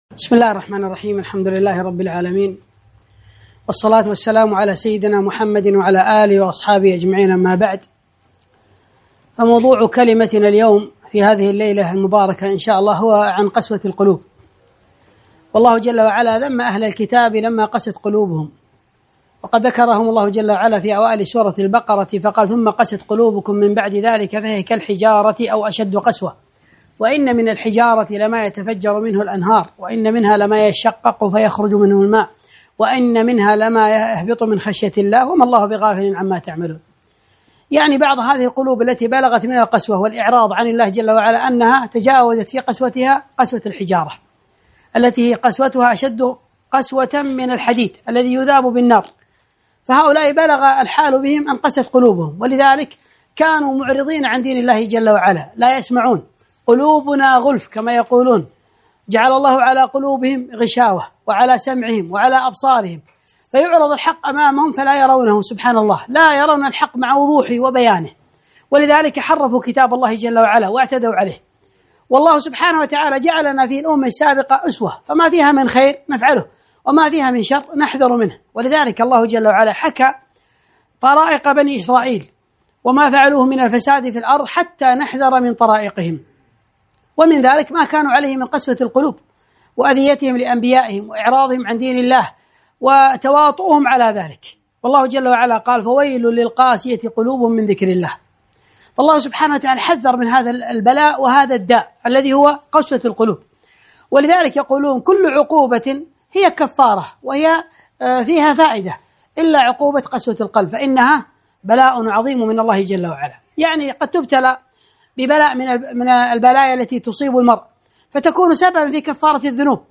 محاضرة - قسوة القلب